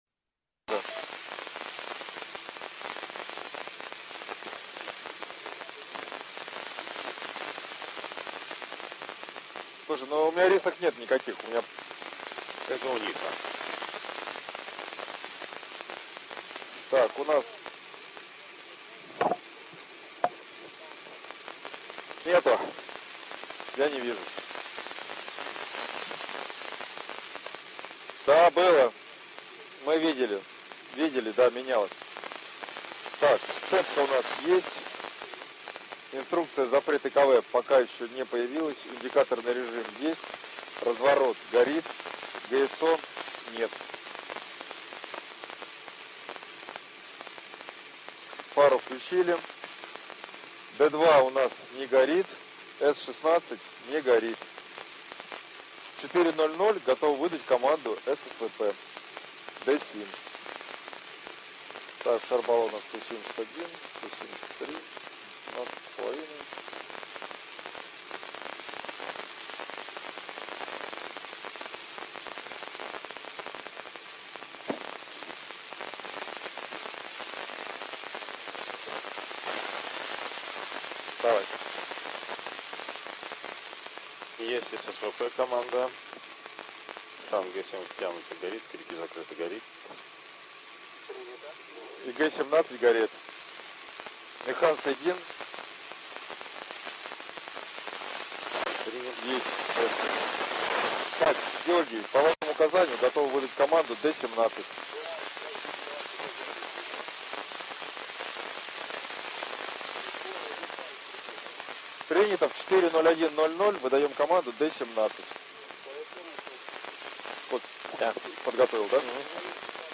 Начало » Записи » Записи радиопереговоров - МКС, спутники, наземные станции
Запись из Иркутской области 1-й виток.